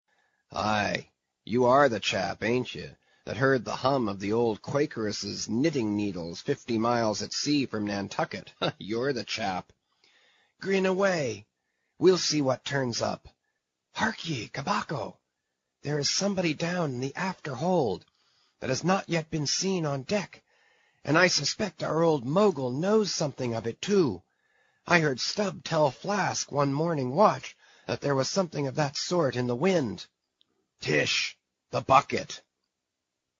英语听书《白鲸记》第467期 听力文件下载—在线英语听力室